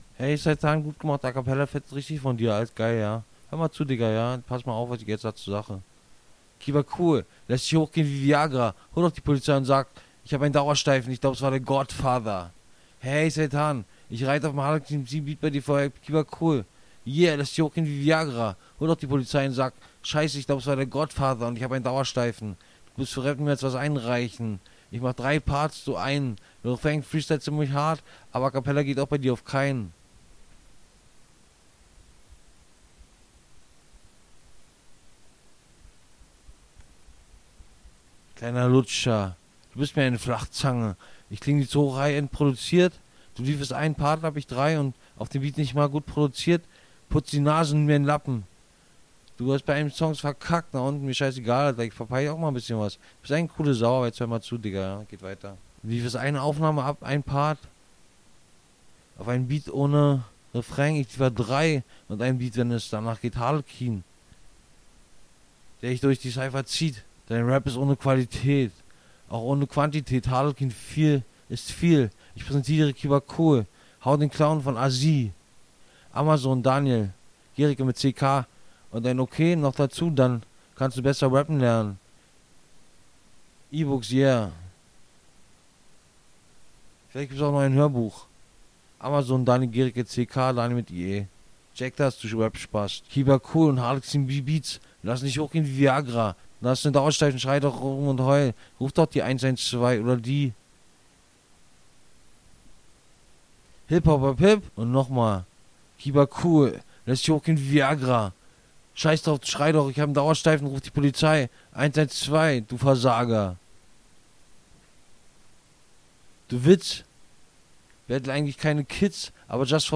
unsportlich, nervig, maximal unlustig, fremdschamerregend, unangenehm zu hören und noch einiges mehr
Du hast den Beat deines Gegners nicht benutzt - Erneut.